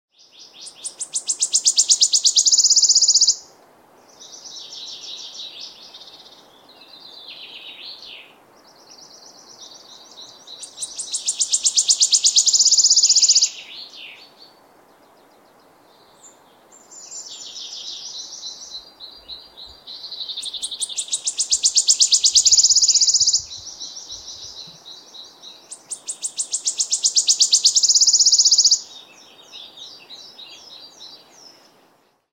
Wood warbler song Bøksanger sang sound effects free download